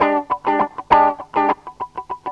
GTR 59 EM.wav